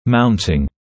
[‘mauntɪŋ][‘маунтин]загипсовка моделей в артикулятор